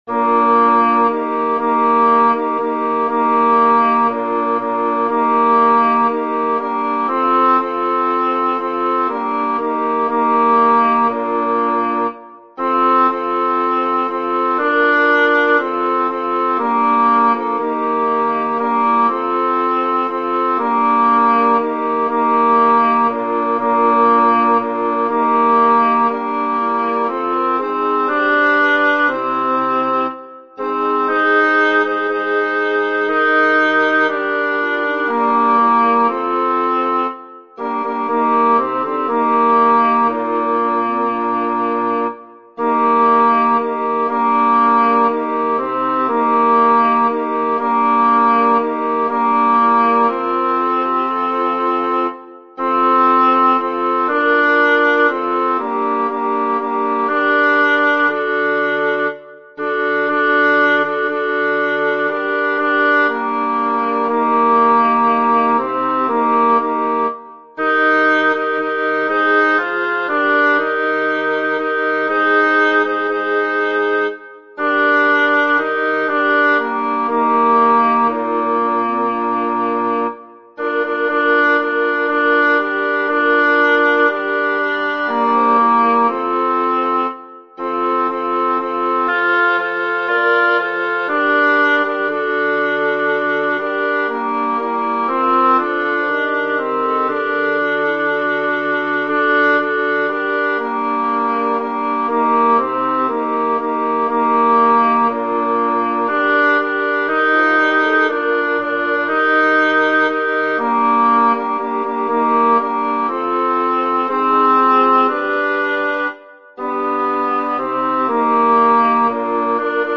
Title: Post haec autem rogavit Composer: Roque Monserrat Lyricist: Number of voices: 4vv Voicing: SATB Genre: Sacred, Motet
Language: Latin Instruments: A cappella